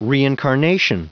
Prononciation du mot reincarnation en anglais (fichier audio)
Prononciation du mot : reincarnation